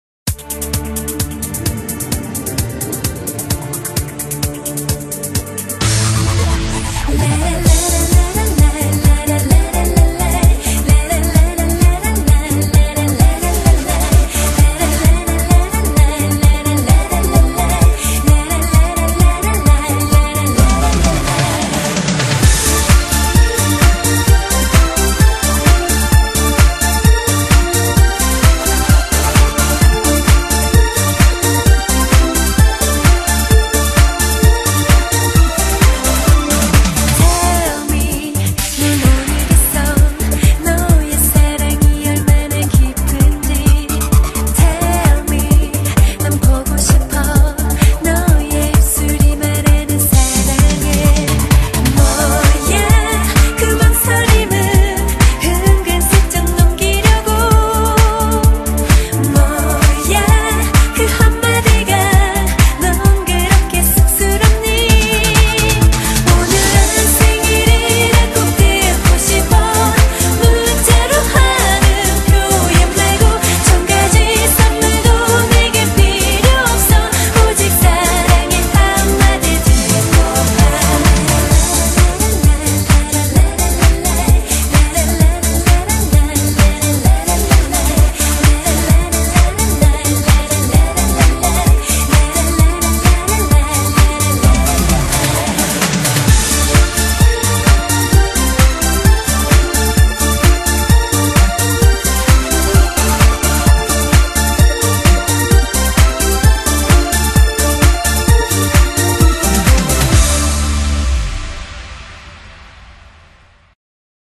BPM130--1
Audio QualityPerfect (High Quality)